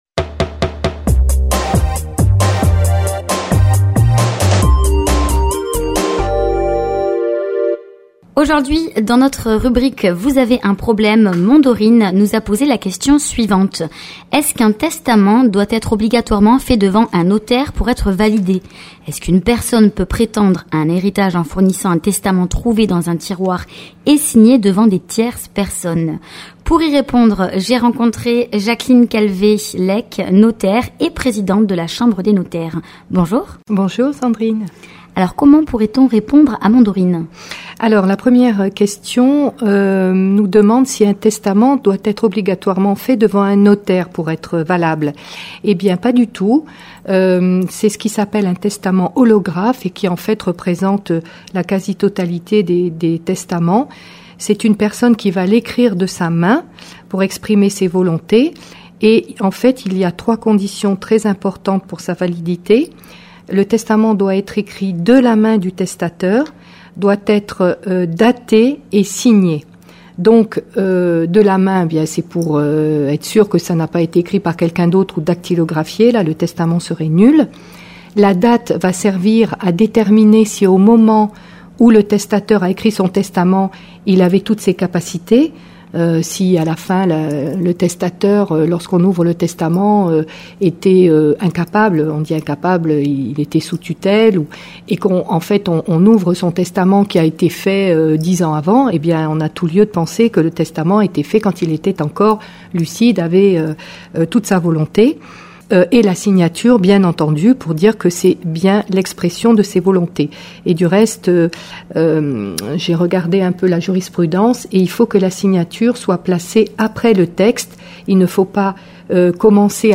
répond à une question d'une auditrice sur les testaments